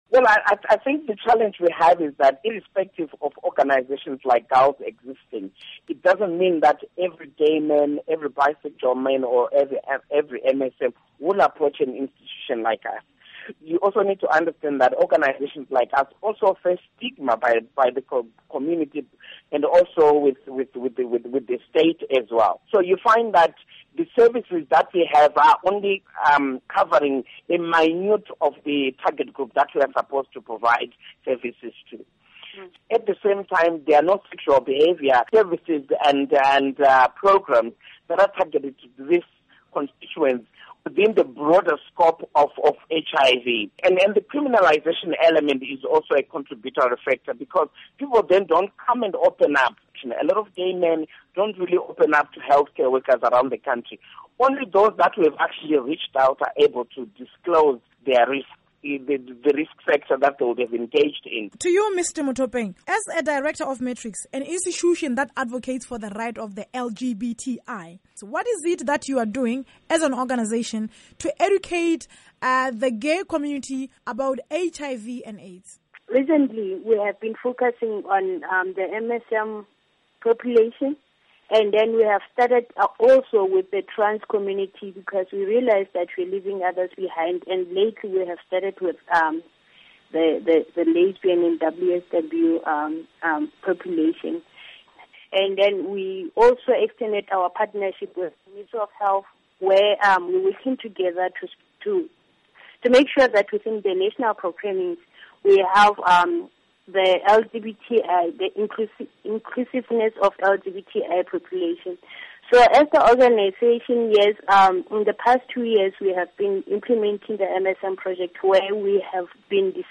Report on LGBT HIV Infections